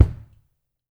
02A KICK  -L.wav